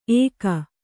♪ ēka